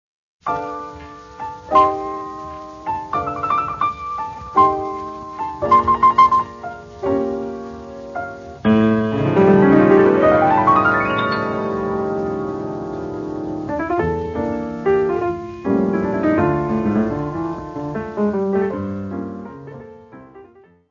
Music Category/Genre:  Jazz / Blues